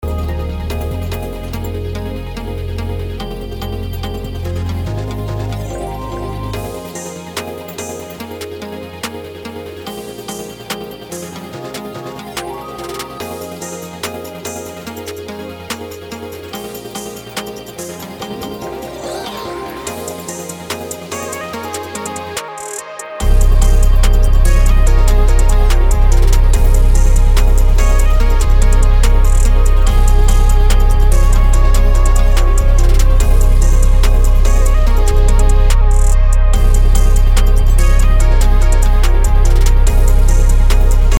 BPM: 144
Key: F minor